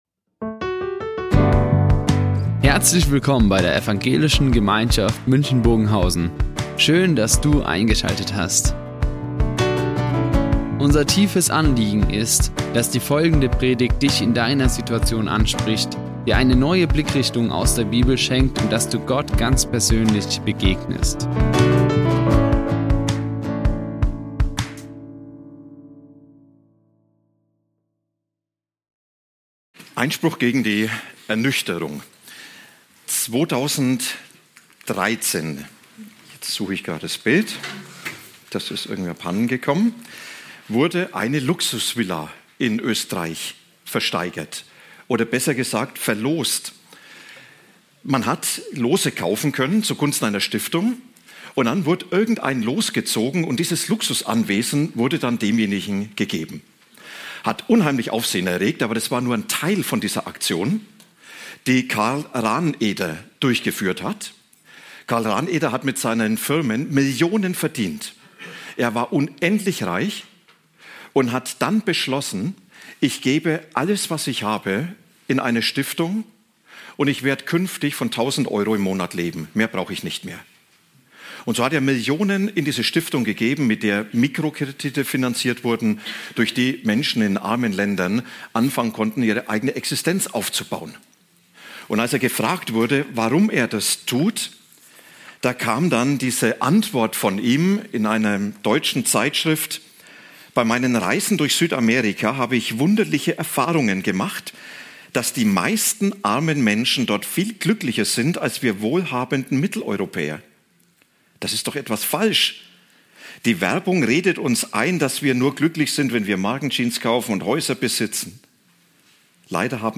EinSPRUCH gegen die Ernüchterung | Predigt Lukas 12, 16-21; Johannes 14,19 ~ Ev.
Die Aufzeichnung erfolgte im Rahmen eines Livestreams.